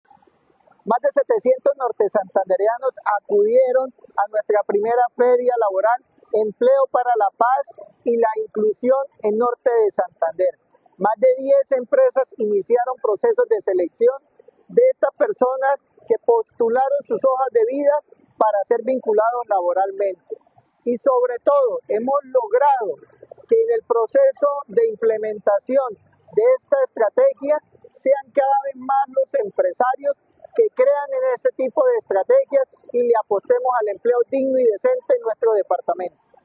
1. Audio de Olger López, secretario de Desarrollo Económico
Audio-de-Olger-López-secretario-de-Desarrollo-Económico.mp3